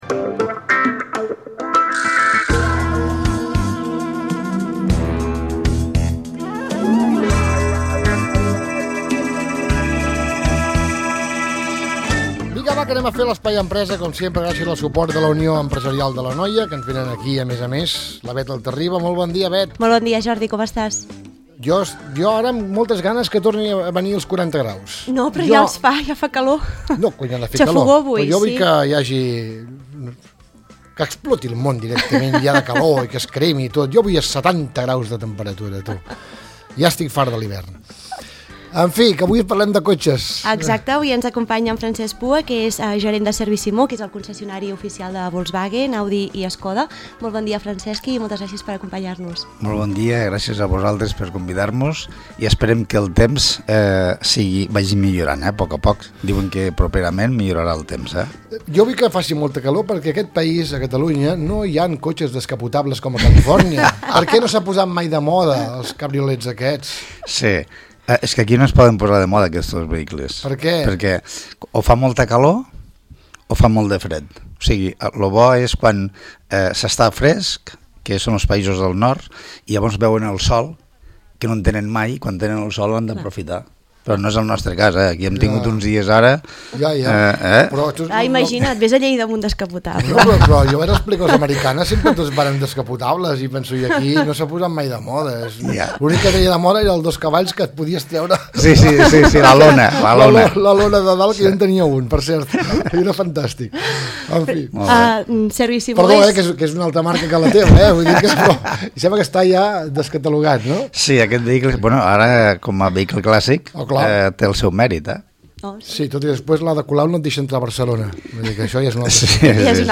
Podcasts d'ESPAI EMPRESA, l'espai radiofònic a Radio Igualada on tractem temes d'interès empresarial entrevistant els associats de la UEA.